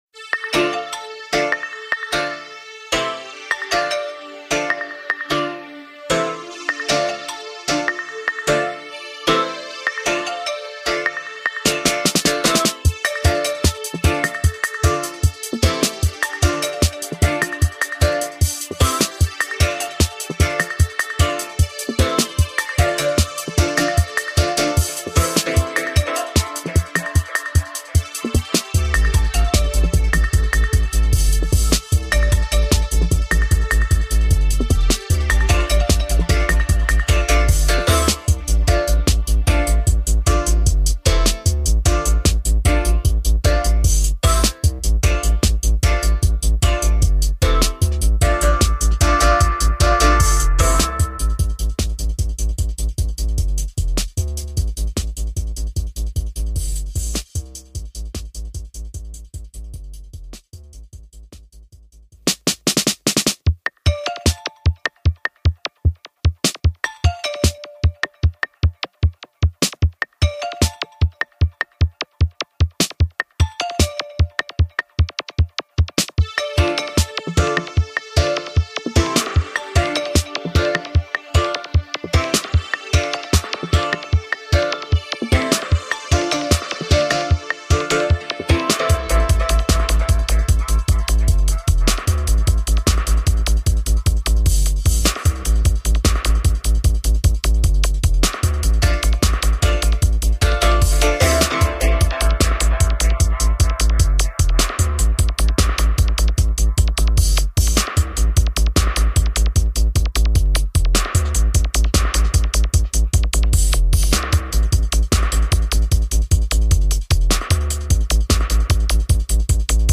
Sample of riddim + dub.